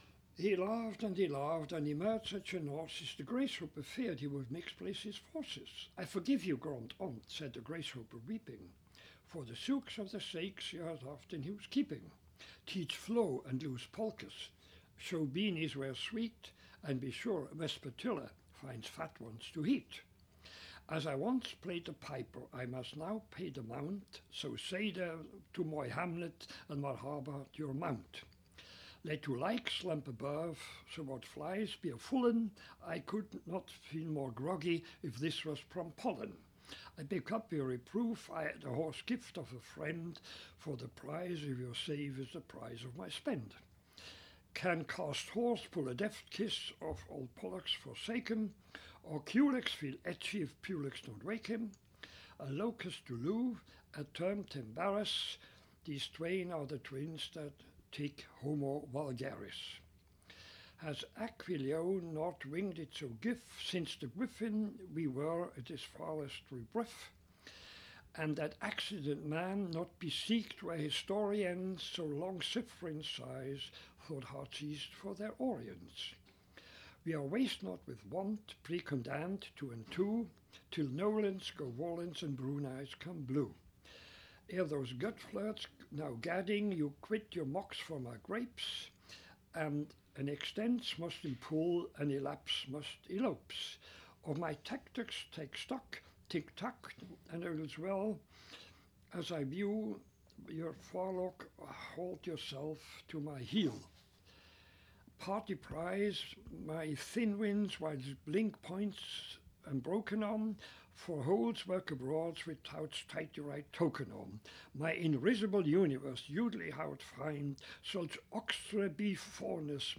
recites The Ondt and the Gracehoper from Finnegans Wake by James Joyce